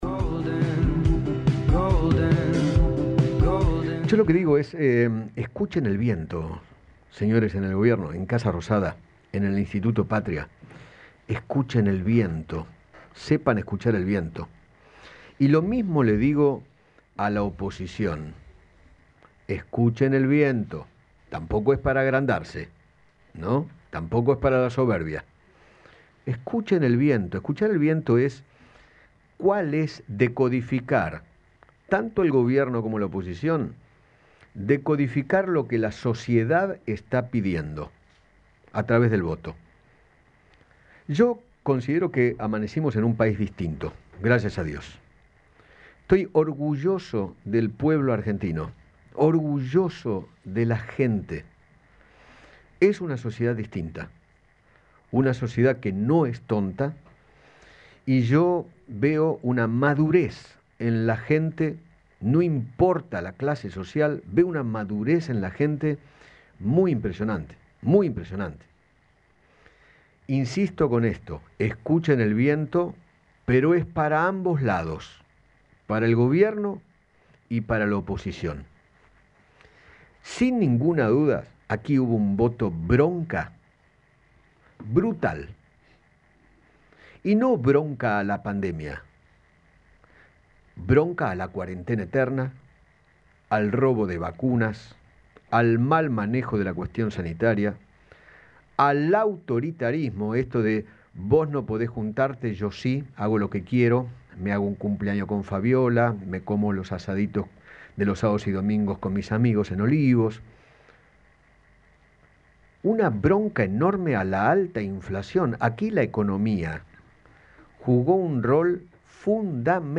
Eduardo Feinmann realizó esta mañana una editorial, luego de la dura derrota del oficialismo en las PASO y consideró que “hubo un voto bronca brutal”.
EDITORIAL.mp3